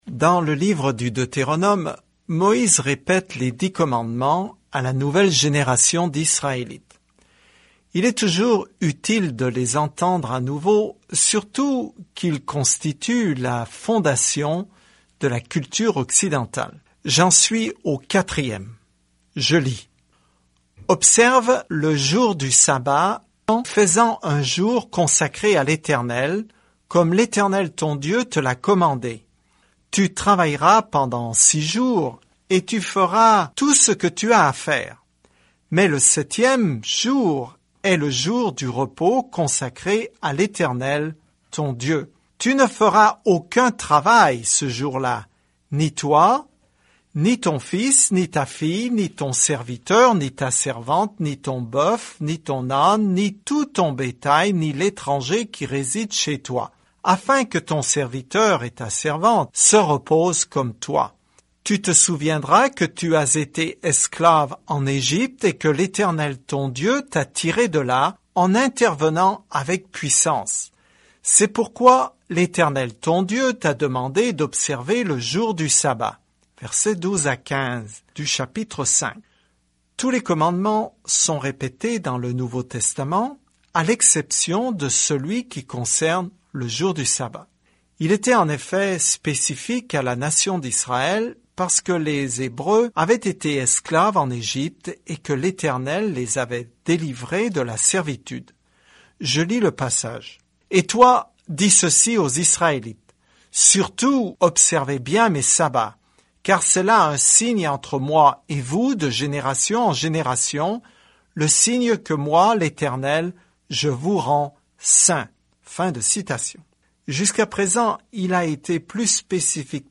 Écritures Deutéronome 5:12-33 Deutéronome 6:1-5 Jour 3 Commencer ce plan Jour 5 À propos de ce plan Le Deutéronome résume la bonne loi de Dieu et enseigne que l’obéissance est notre réponse à son amour. Parcourez quotidiennement le Deutéronome en écoutant l’étude audio et en lisant certains versets de la parole de Dieu.